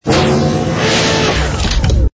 ui_load_cargo.wav